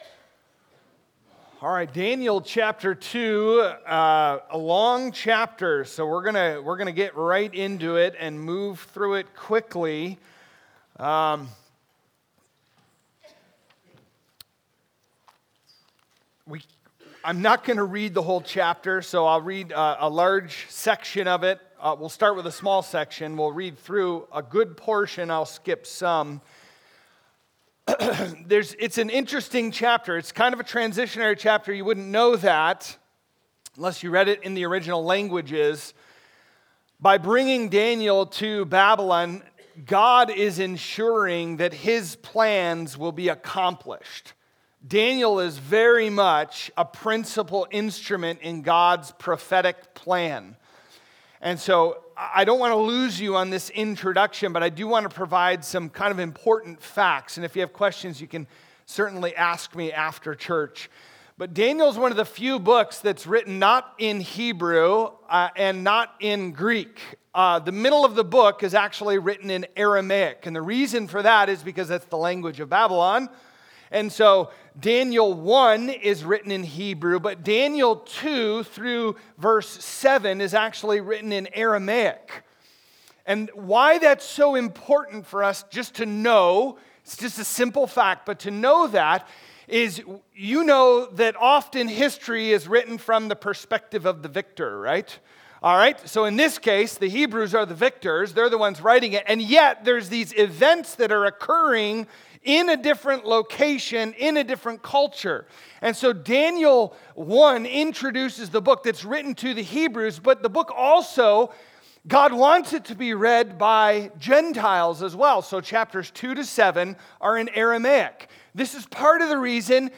Sermons | Trinity Baptist Church